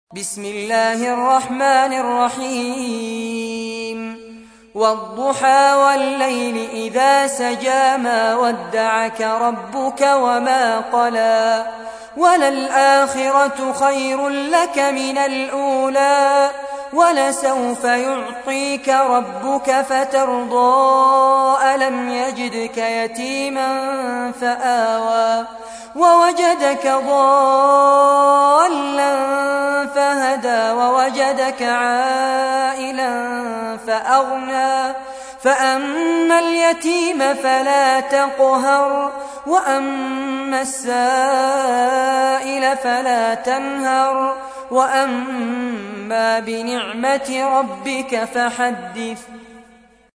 تحميل : 93. سورة الضحى / القارئ فارس عباد / القرآن الكريم / موقع يا حسين